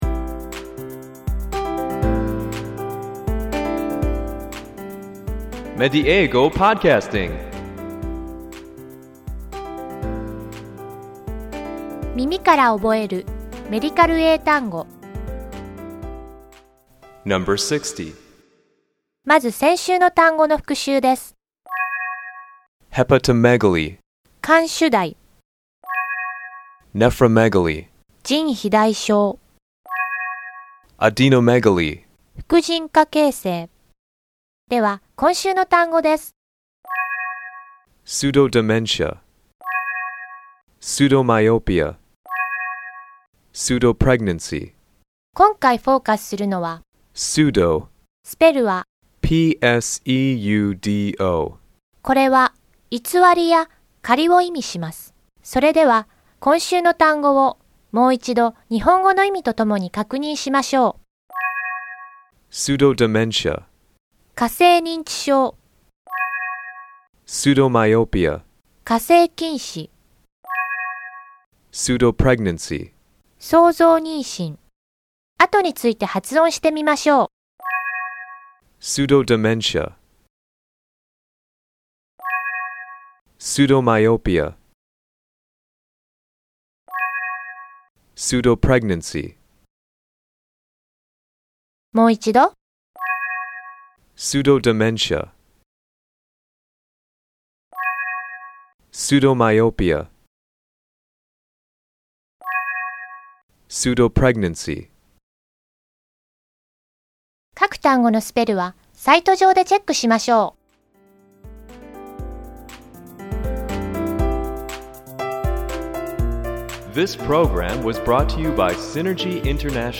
ネイティブの発音を聞いて，何度も声に出して覚えましょう。